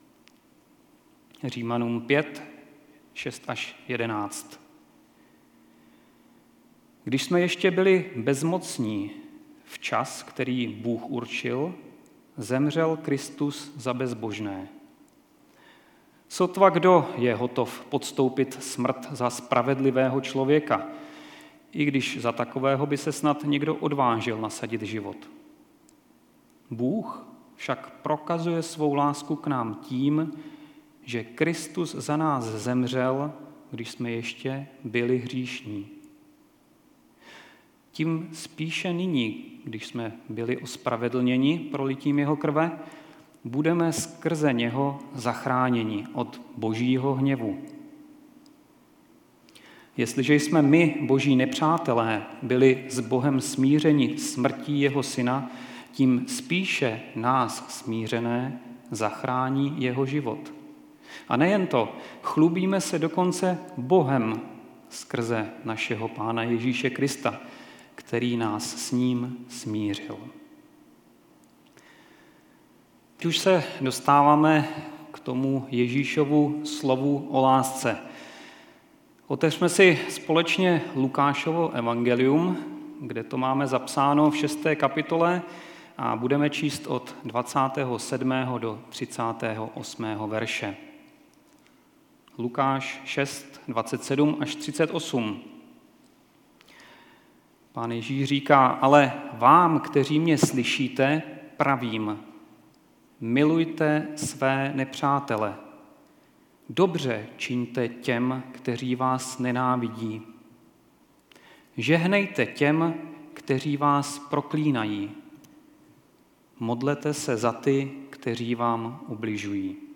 3. kázání ze série: víra, naděje, láska - ale největší z té trojice je láska (Římanům 5,6-11; Lukáš 6,27-38)
Kategorie: Nedělní bohoslužby